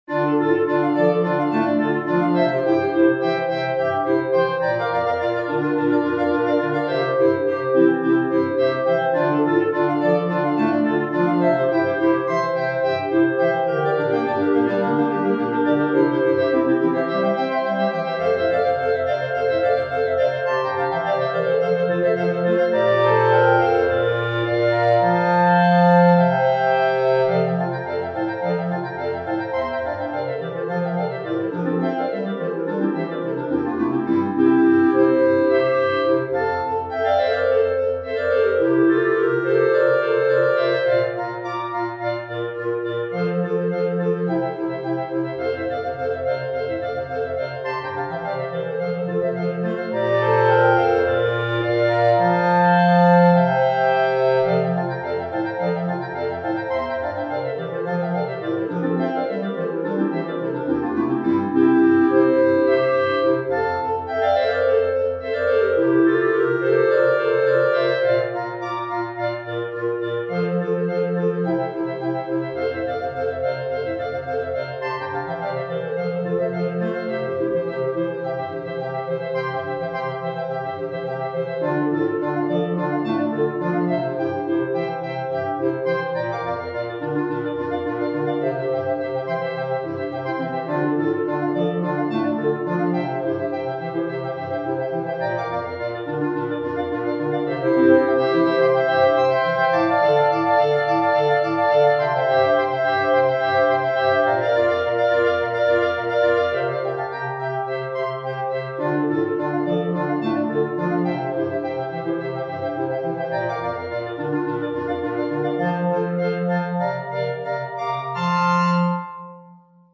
per Quartetto di Clarinetti